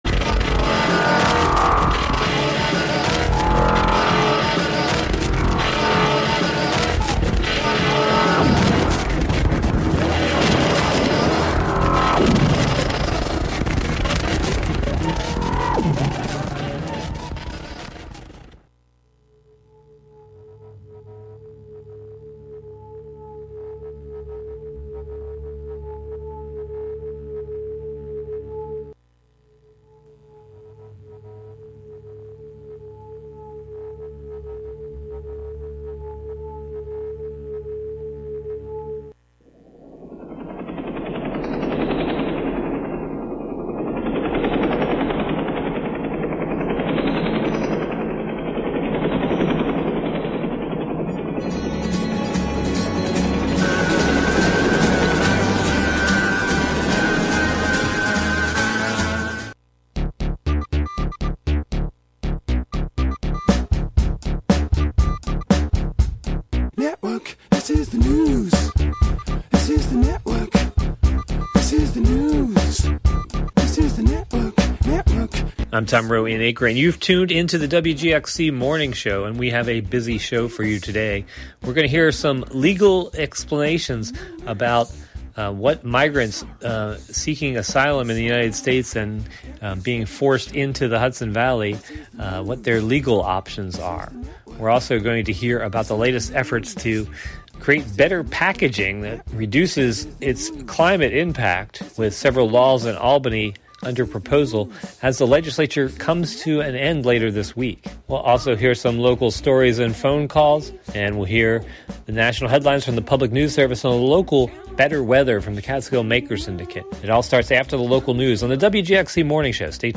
With the legislative session scheduled to end this Thursday, Environmental Chairs State Senator Pete Harckham and Assemblymember Deborah Glick held a press conference on June 5 to urge passage of their recently rewritten Packaging Reduction and Recycling Infrastructure Act. The legislation would reduce packaging, including plastic packaging, by half over the next 12 years; Ban certain toxic chemicals from being used for packaging; and, Prohibit so-called chemical recycling from being counted as recycling.
The "WGXC Morning Show" is a radio magazine show featuring local news, interviews with community leaders and personalities, reports on cultural issues, a rundown of public meetings and local and regional events, with weather updates, and more about and for the community, made mostly through volunteers in the community through WGXC.